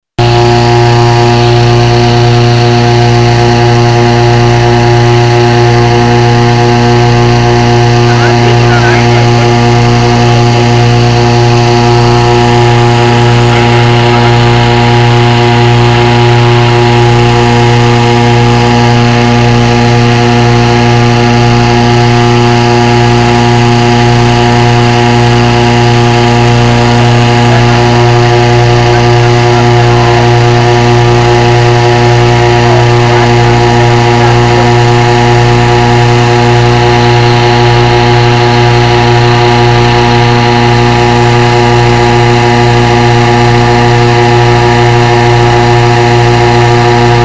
Audioaufnahme des Gebläses, mit dem der Ballon aufgeblasen wurde:
Geblaese.MP3